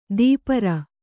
♪ dīpara